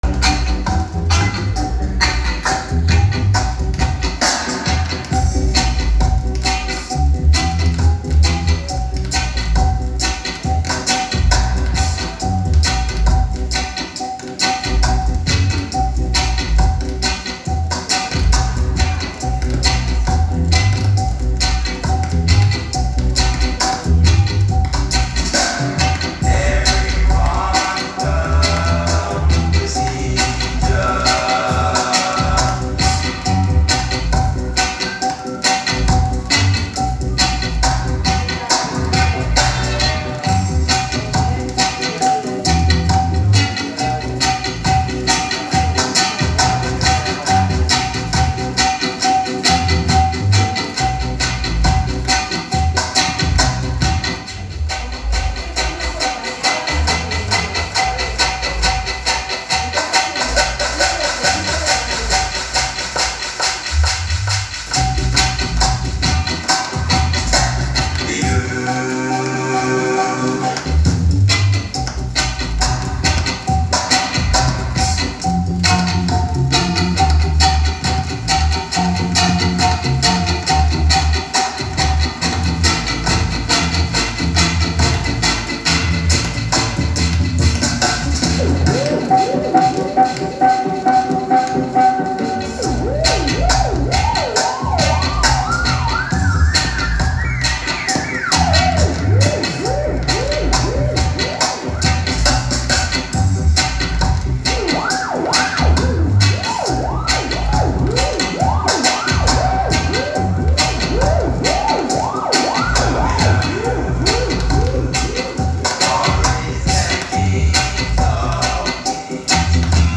Warm Up Dub Jam Session
Every second sunday of each month At Ass. Cult. "Los Trecers" - Barcelona Area